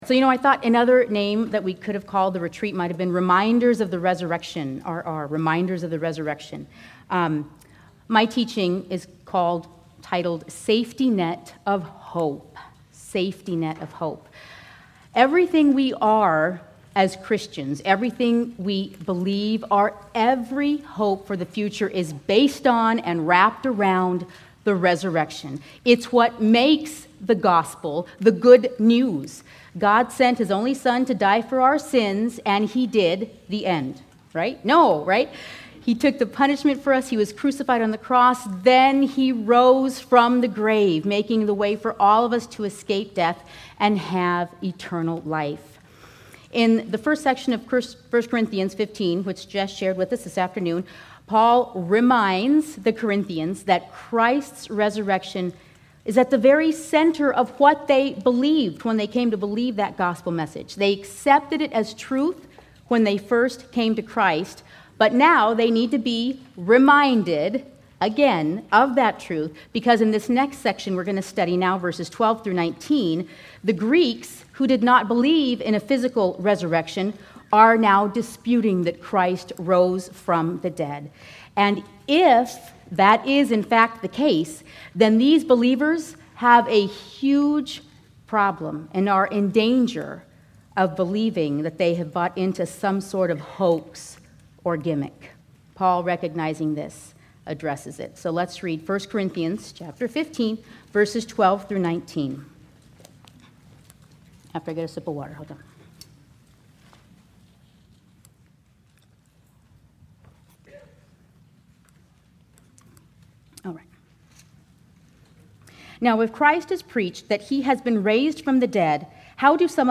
Women's Retreat 2014